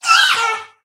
Cri de Wushours dans Pokémon HOME.